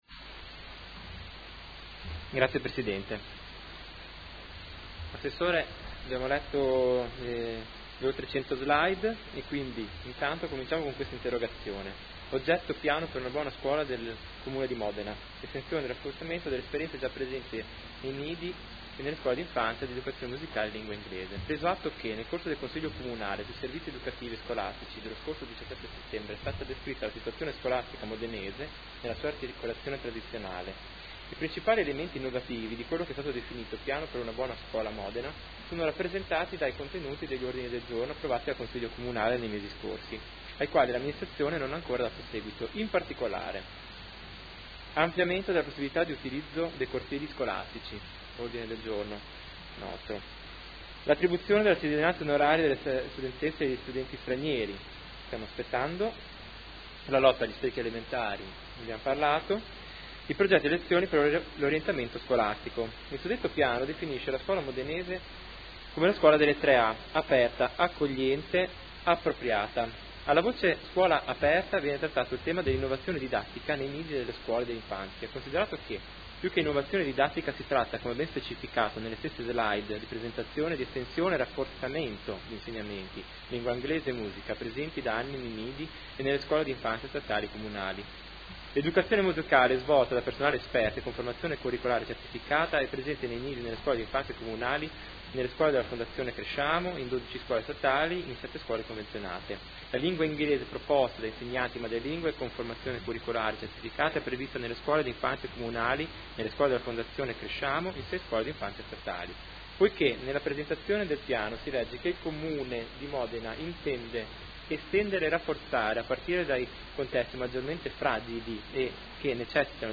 Seduta del 1° ottobre. Interrogazione del Consigliere Chincarini (Gruppo Per me Modena) avente per oggetto: Piano per una Buona Scuola del Comune di Modena.